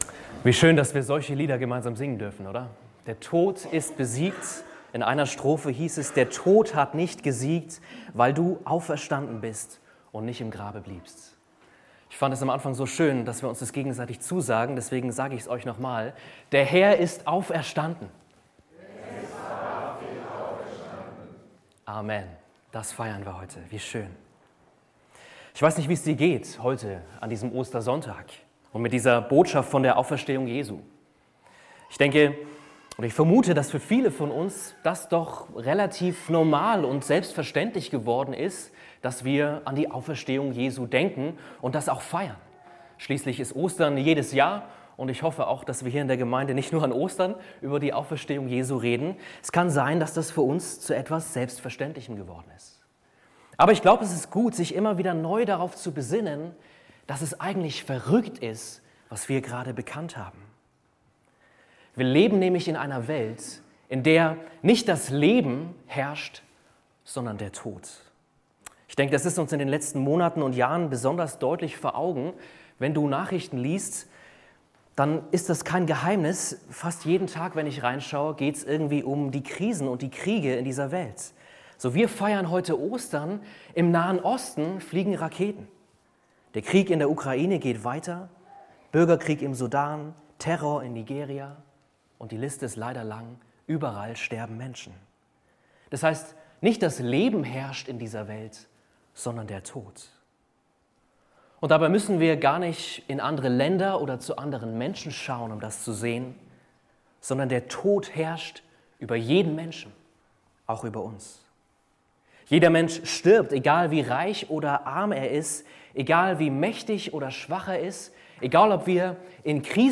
Ostergottesdienst – Schau auf den Auferstandenen (Offenbarung 1, 12-20) – EFG Bayreuth